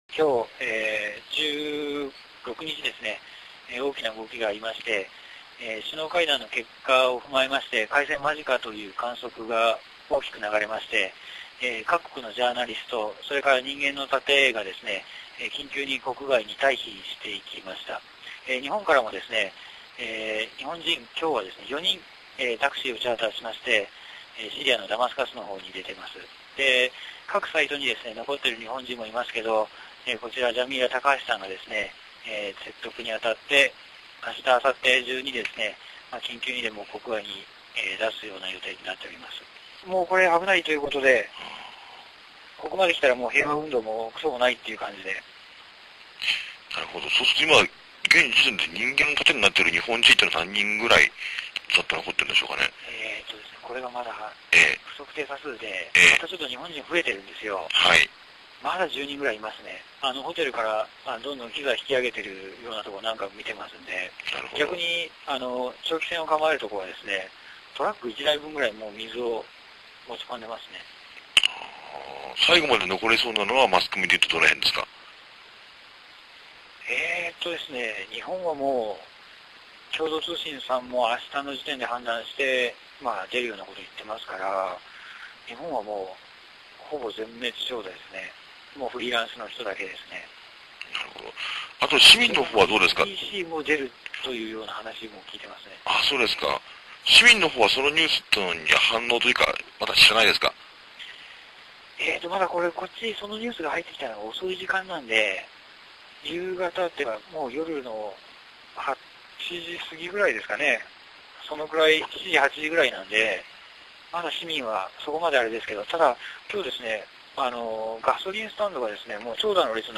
音声リポート